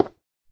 stone4.ogg